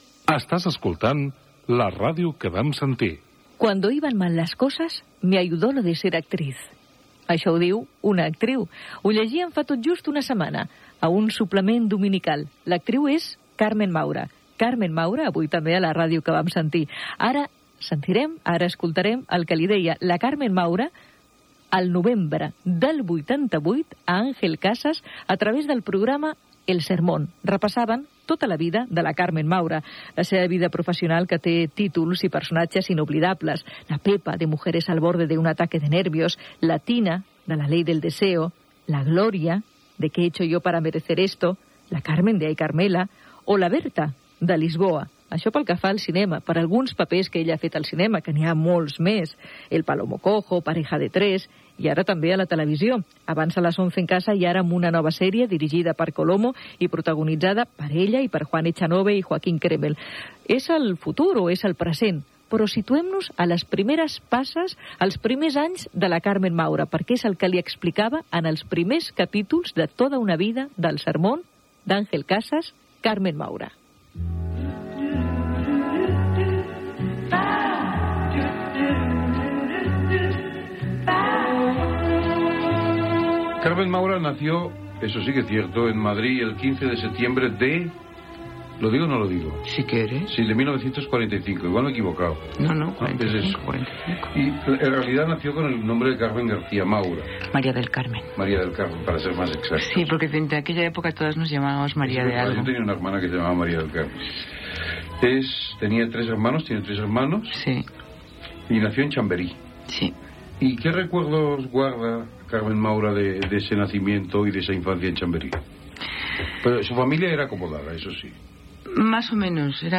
Indicatiu del programa, record de l'entrevista d'Àngel Casas a Carmen Maura el novembre de 1988 al programa de la cadena SER "El Sermón"
Divulgació